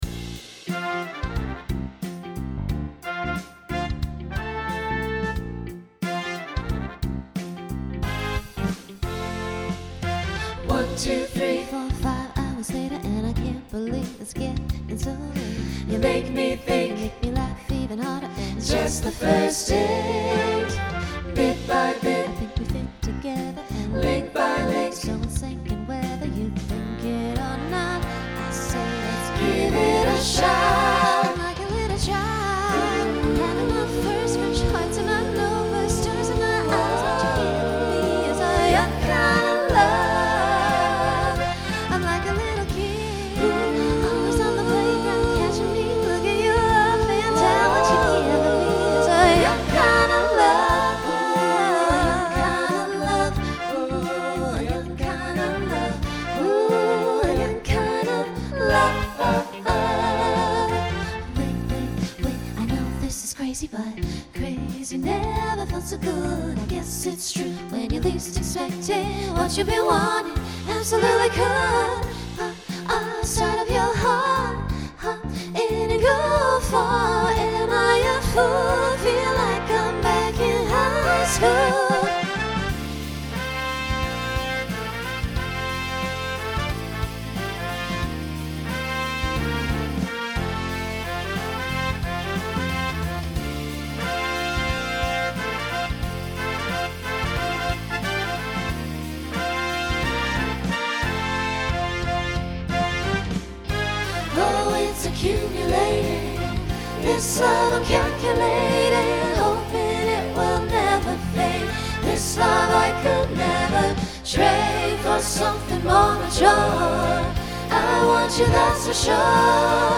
Starts with a quartet to accommodate a costume change.
Genre Pop/Dance Instrumental combo
Transition Voicing SATB